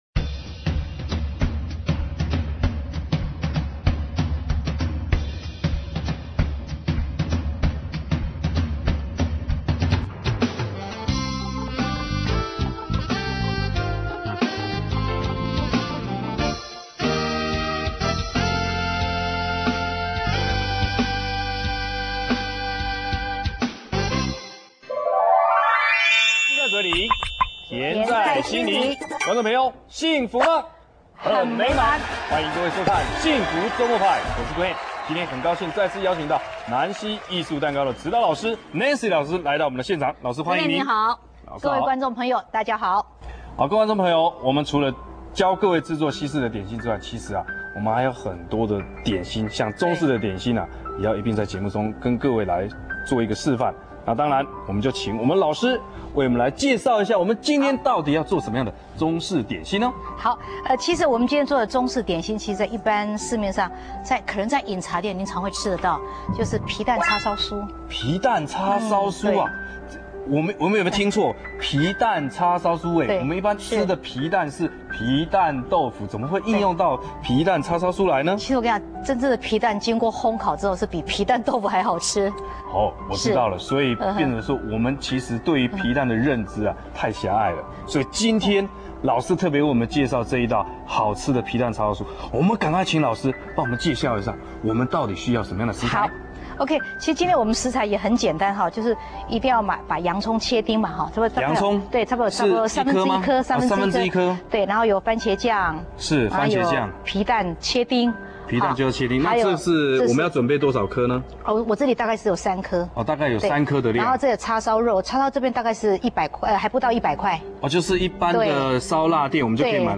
[點心] 幸福週末派--皮蛋叉燒酥(電視教學) - 看板baking